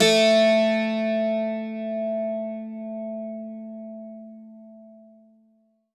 52-str04-bouz-a2.wav